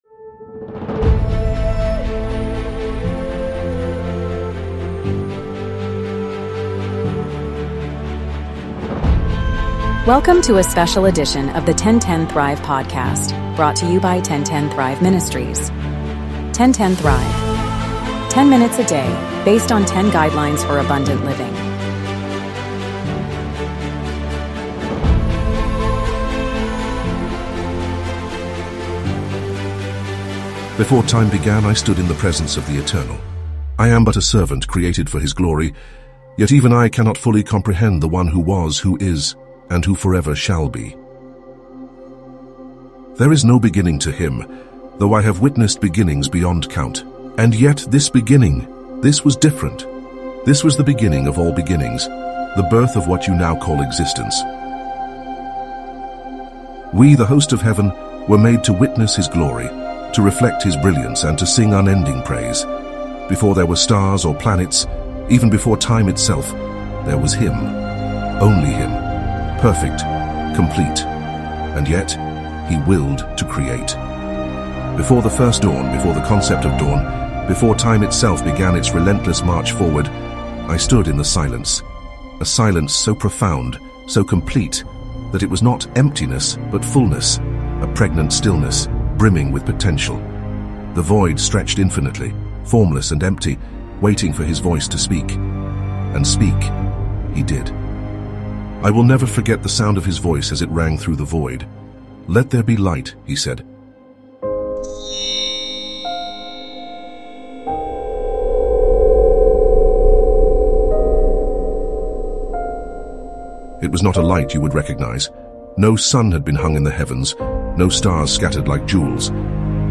This dramatic monologue captures the awe-inspiring moment of creation through the eyes of a celestial being who witnessed the birth of the universe. From the formless void to the emergence of light and the intricate design of life, the monologue recounts each day of creation as an intentional act of divine artistry.